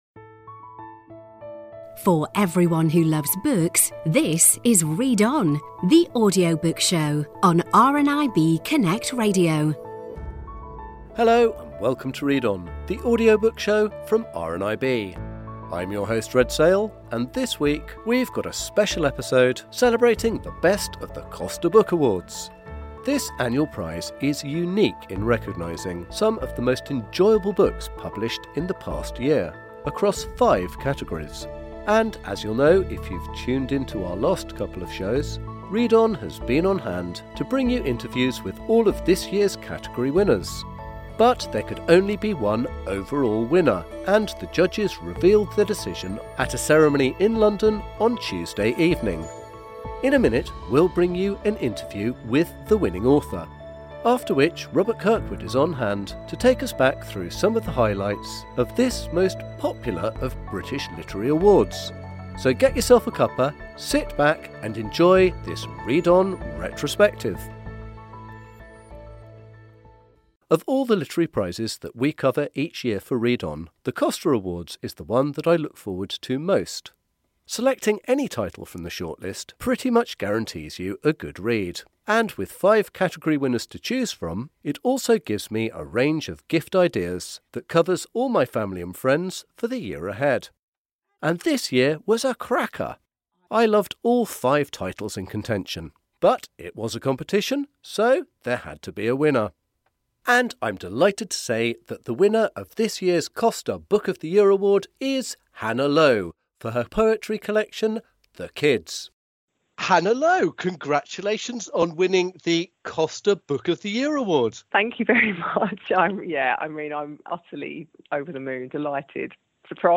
Our interview with Hilary Mantel when ‘Bring Up the Bodies’ won Best Novel in 2013.